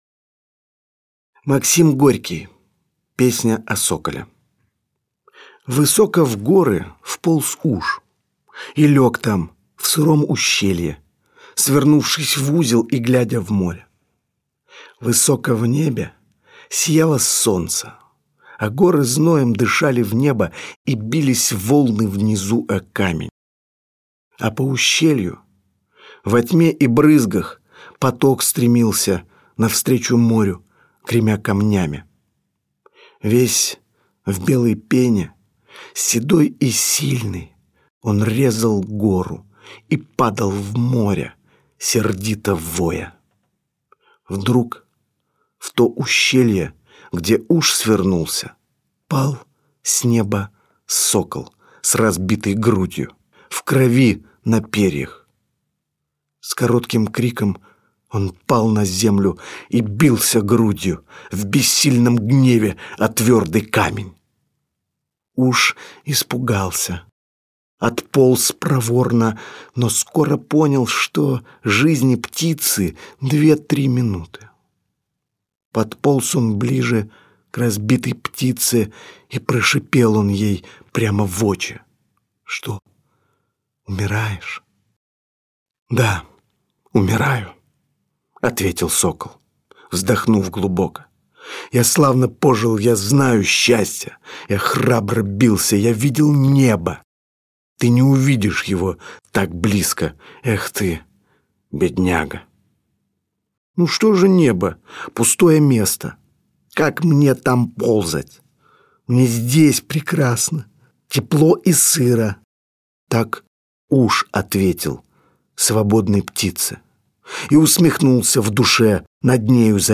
Gorkiy-Pesnya-o-Sokole-isp.-Dmitrij-Nagiev-AUDIOHRESTOMATIYA-stih-club-ru.mp3